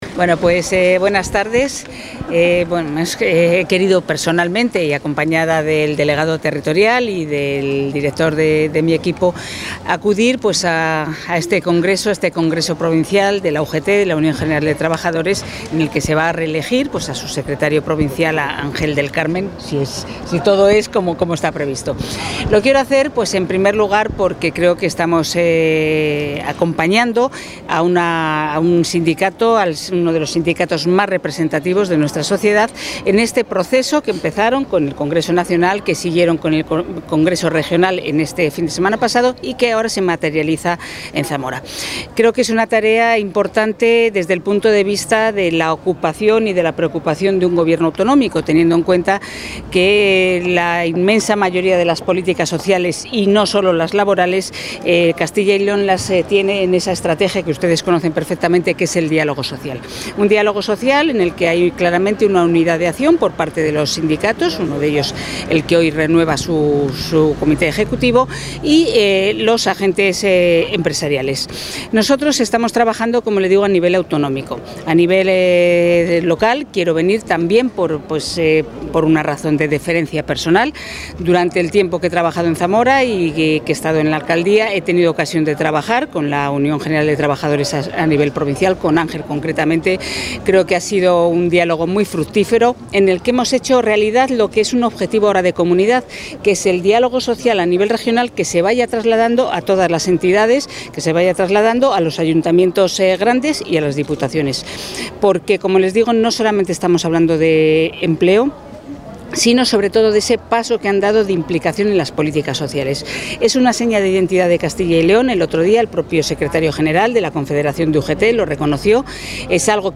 Declaraciones de Rosa Valdeón en el XII Congreso UGT en Zamora.